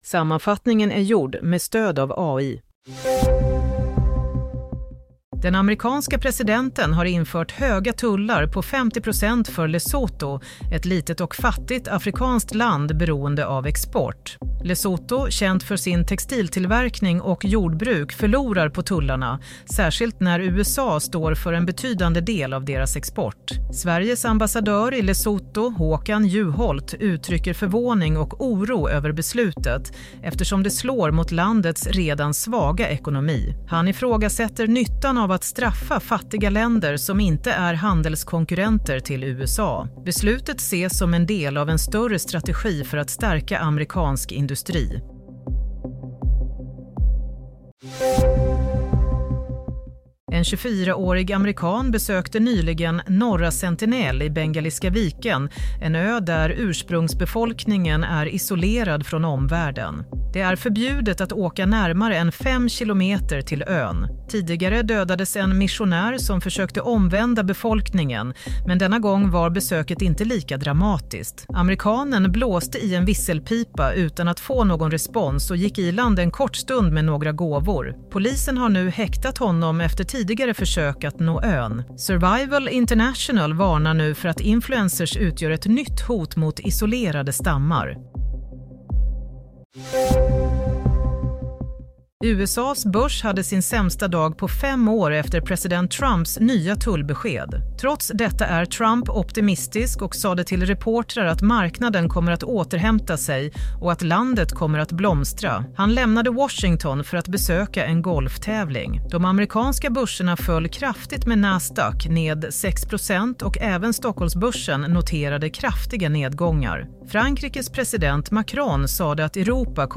Nyhetssammanfattning - 4 april 07:00
Sammanfattningen av följande nyheter är gjord med stöd av AI. - Lesotho värst drabbat av strafftullarna: Kämpar för sin överlevnad - Turist gripen – smög i land på förbjudna ön - Trump: Börsen kommer att rusa Redaktör